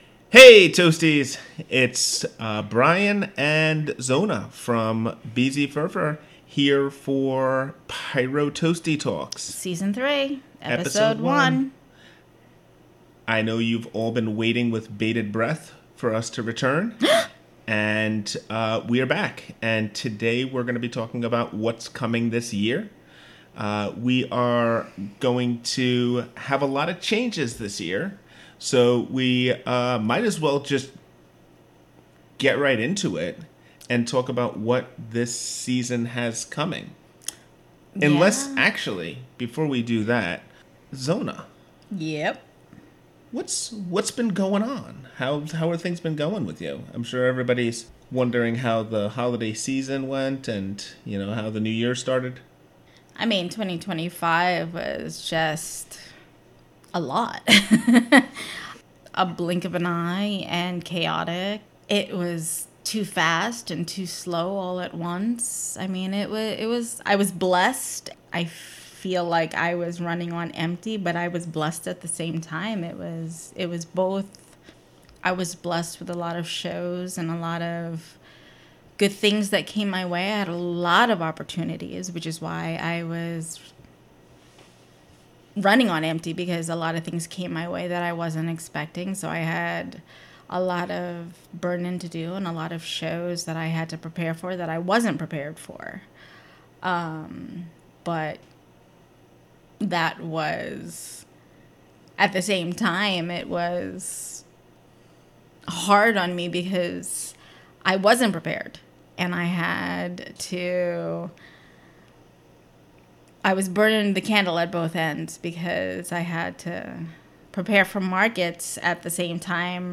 Through honest, down-to-earth discussions, we explore the craft of wood burning, artistic process, creative business, and the experiences that shape life as an artist.